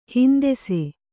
♪ hindese